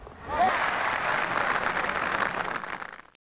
Applause
Applause.wav